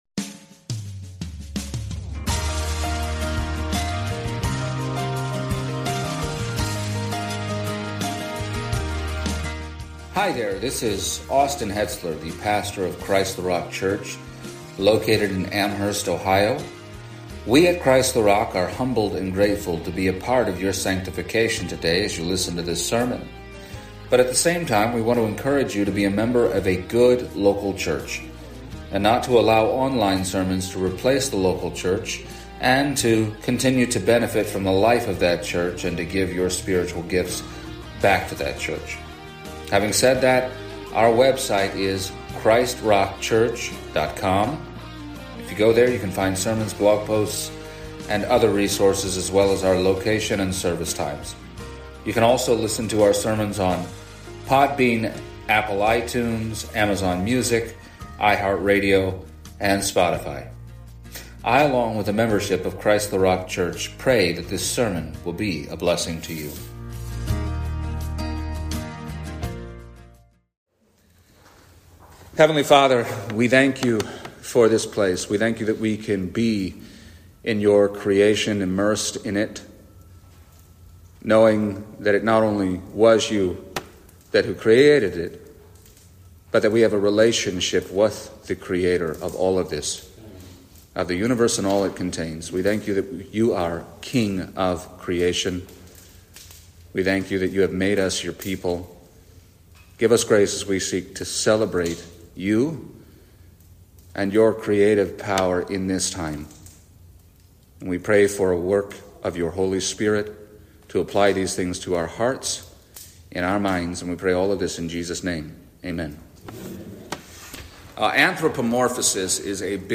The first of two messages given at our annual CtRC church Camp